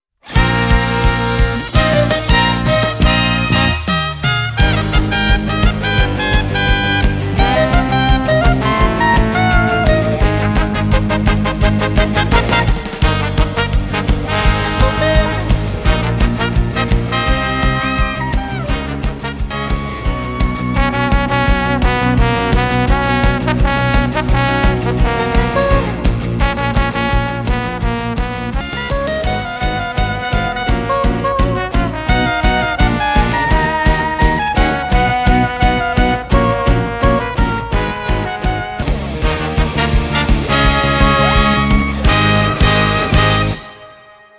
難易度 分類 駆足170 時間 ４分３４秒
編成内容 大太鼓、中太鼓、小太鼓、シンバル、トリオ 作成No ２４５